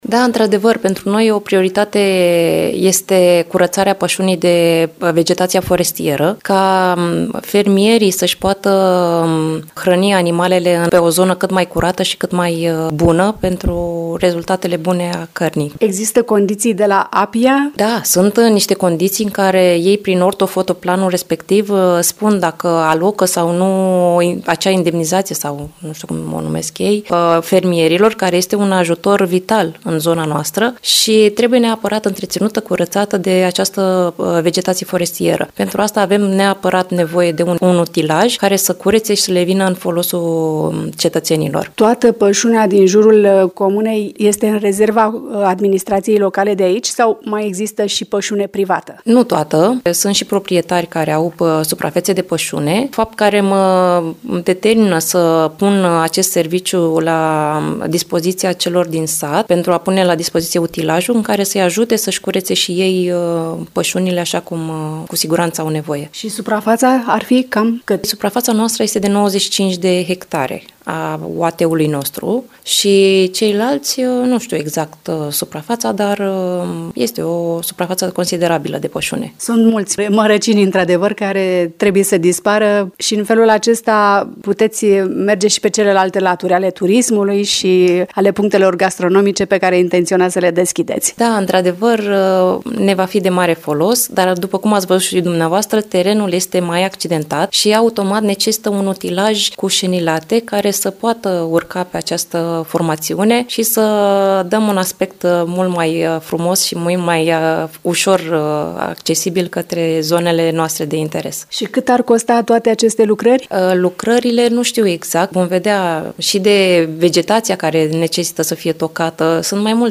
Aproximativ 100 de ha de pășune din jurul satului Dumbrăveni sunt invadate de mărăcini și arbuști uscați. Primăria Dumbrăveni caută soluții pentru a elibera terenul de vegetația forestieră uscată, terenul fiind unul accidentat, spune primarul Luminița Șandru.